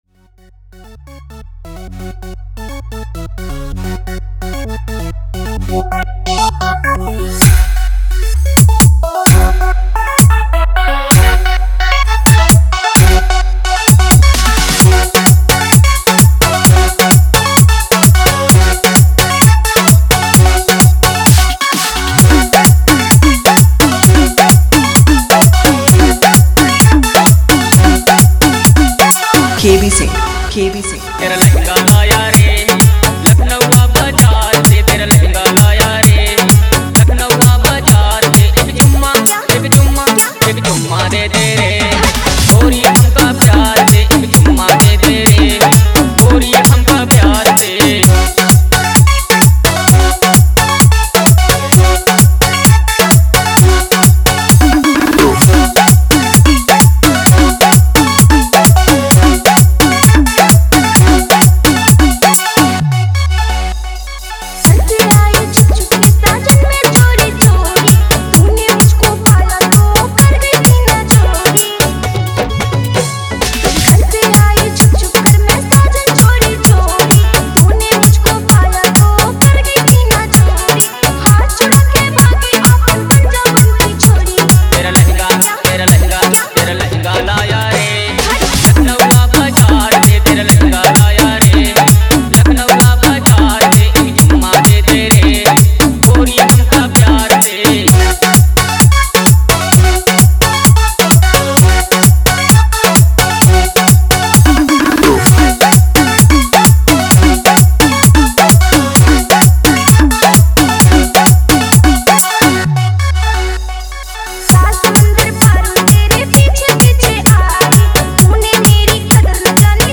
Party Song Dj Remix